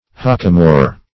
Search Result for " hockamore" : The Collaborative International Dictionary of English v.0.48: Hockamore \Hock"a*more\, n. [See 1st Hock .] A Rhenish wine.